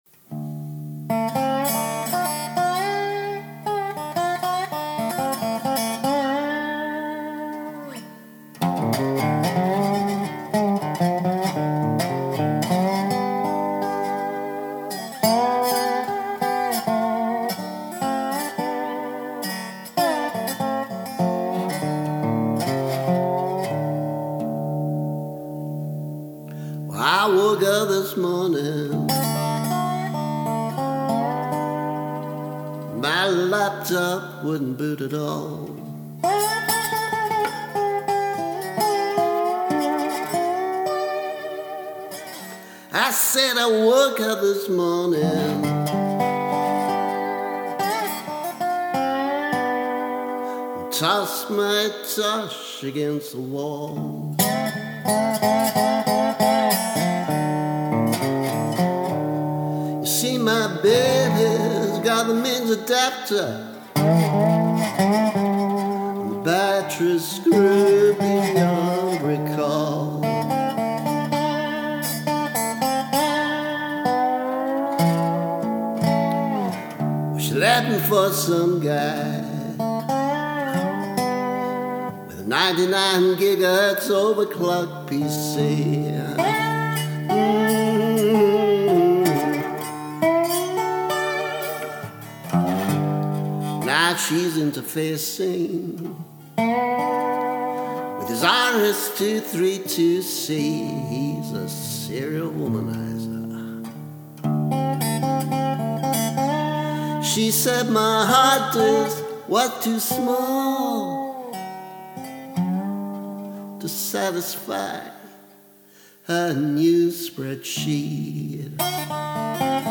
Remastered versions
I wouldn’t have mentioned any of this if it weren’t for a ludicrous conversation in a pub with someone who apparently thought I was setting PC for Dummies to music rather than writing a mildly amusing blues parody.